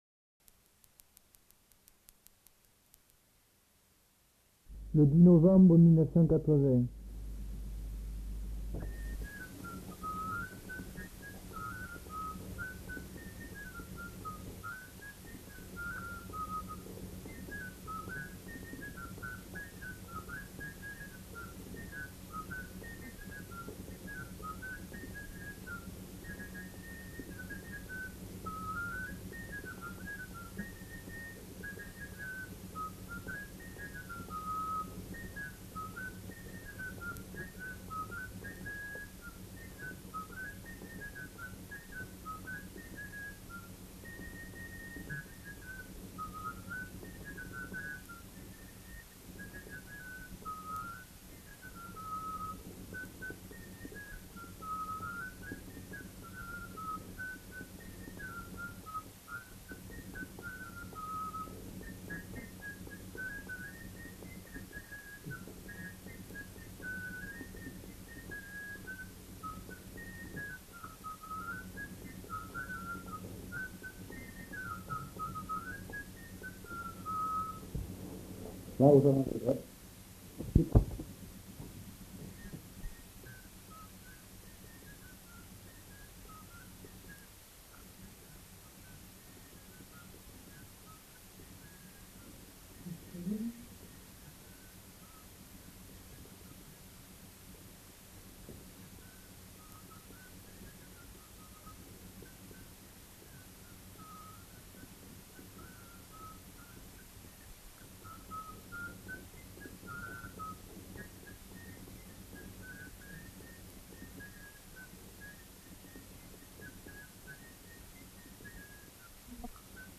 Répertoire de danses du Gabardan joué à la flûte de Pan et à l'harmonica
enquêtes sonores